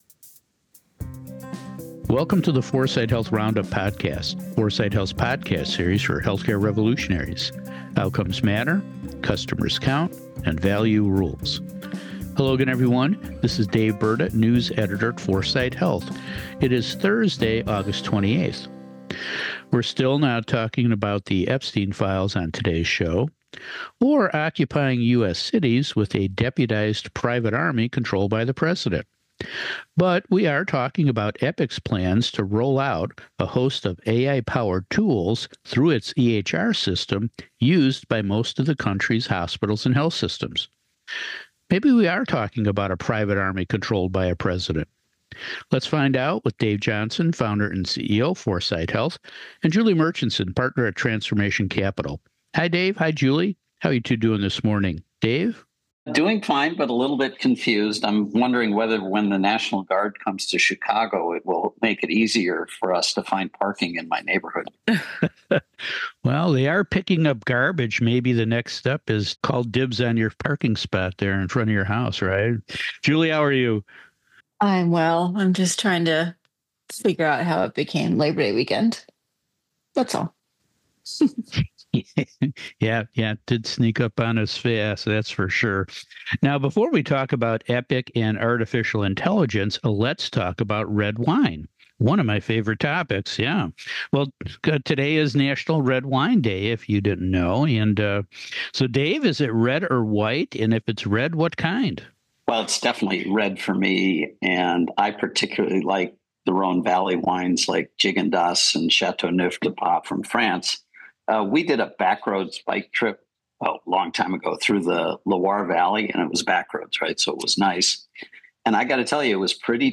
Dominant EHR vendor Epic is going all in on AI-powered tools for patients, payers and providers. We talked about who wins and who loses on the new episode of the 4sight Health Roundup podcast.